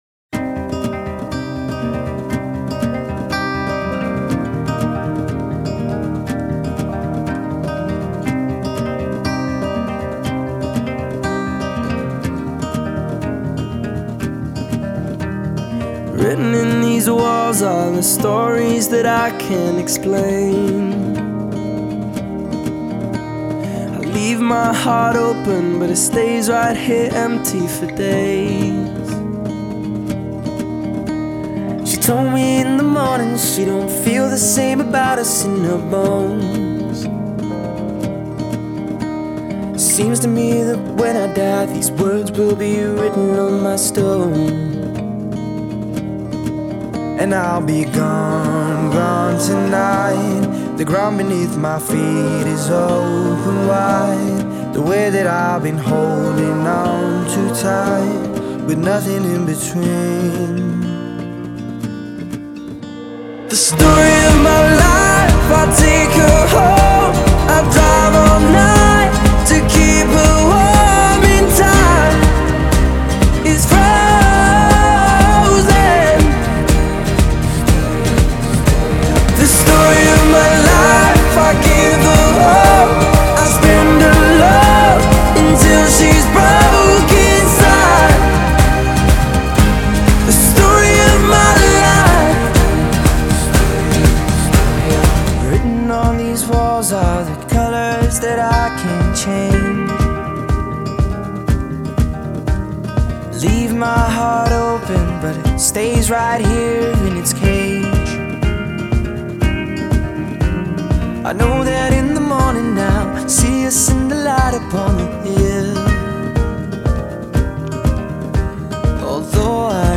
Жанр: поп-музыка, поп-рок, данс-поп